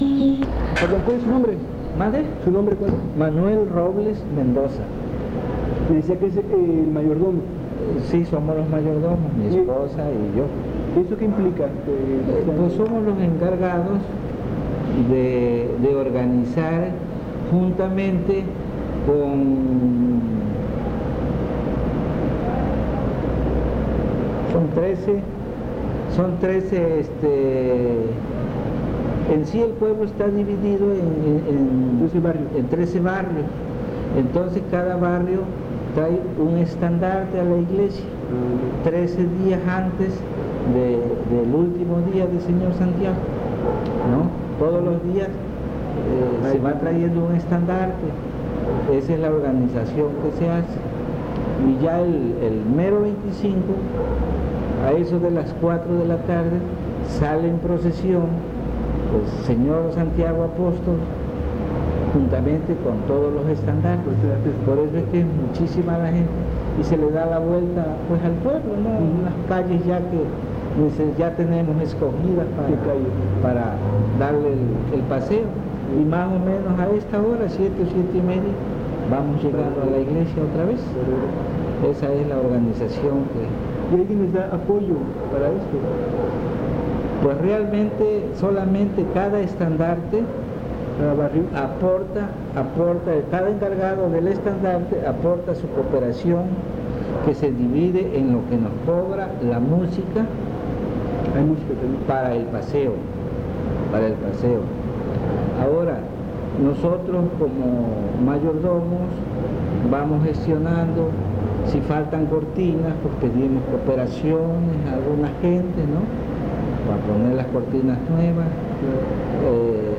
Entrevista
Ubicación San Andrés Tuxtla, Veracruz, Mexico